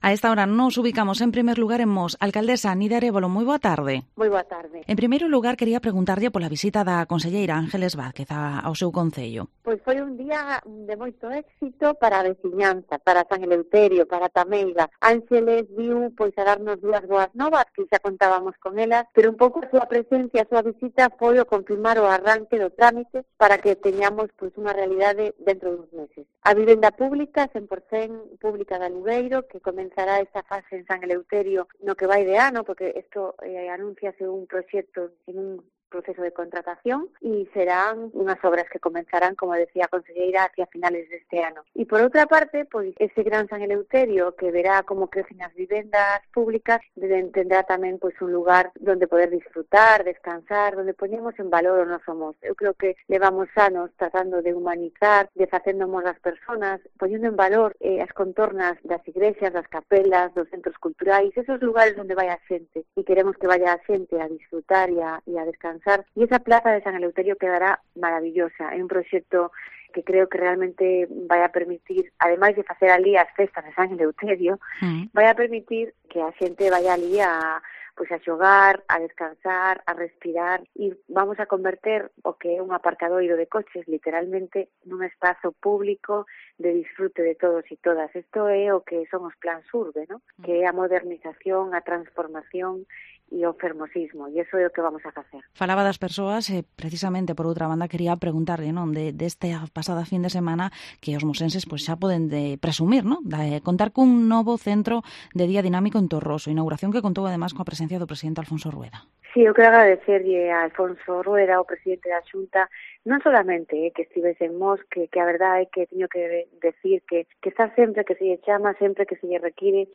Entrevista Alcaldesa de Mos, Nidia Arévalo